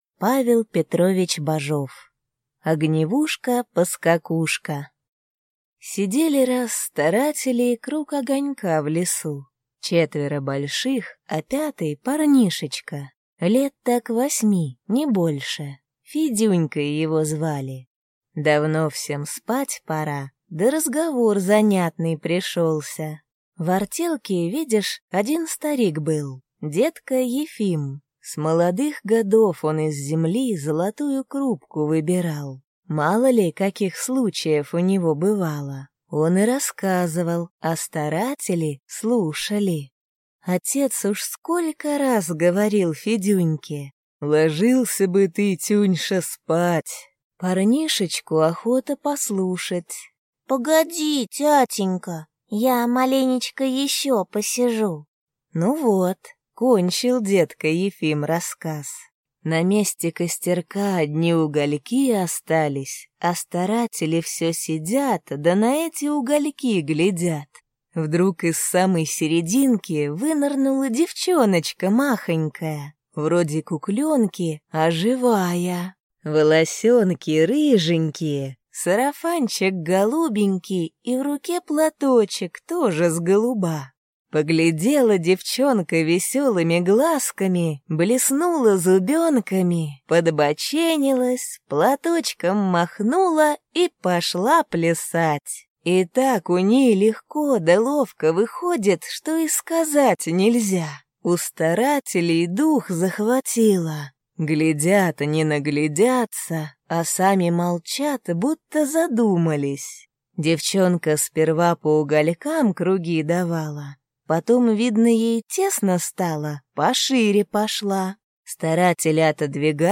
Аудиокнига Огневушка-Поскакушка | Библиотека аудиокниг